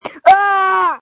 Screams from January 8, 2021
• When you call, we record you making sounds. Hopefully screaming.